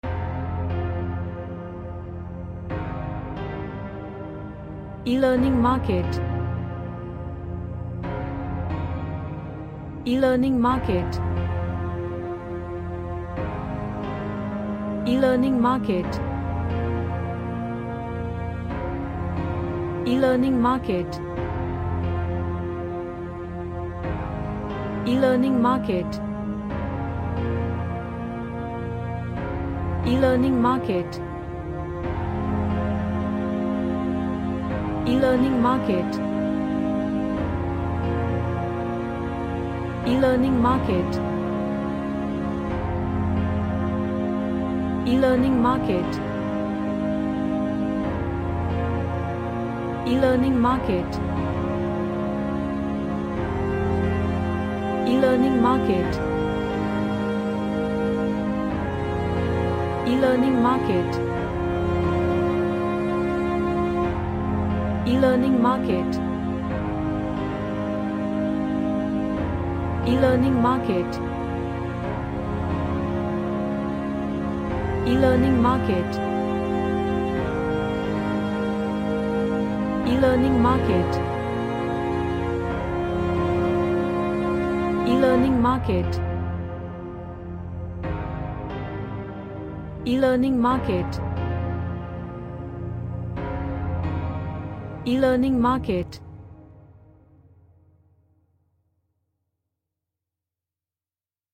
A dark sad emotional cinematic score.
Sad / NostalgicEmotional